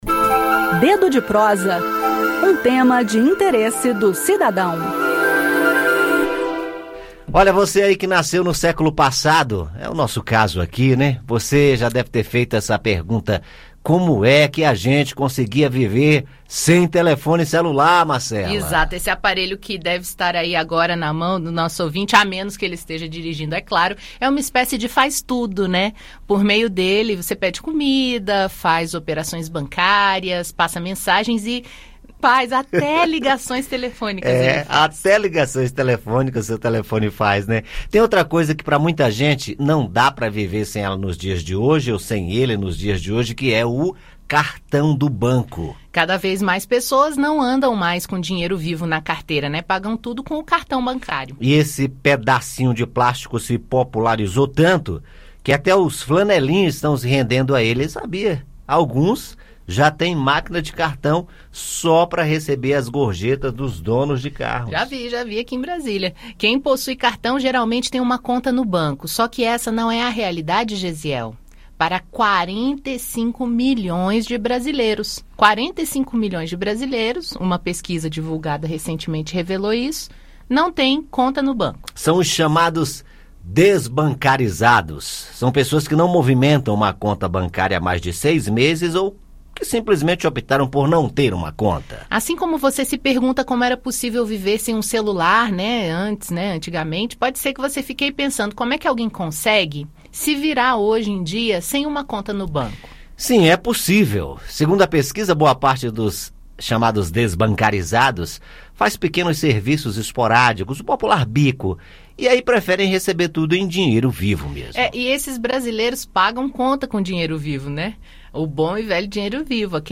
No "Dedo de Prosa" de hoje, sexta-feira (23), o tema é a vida à margem do sistema bancário, havendo, atualmente, 45 milhões de brasileiros "desbancarizados". Ouça o áudio com o bate-papo.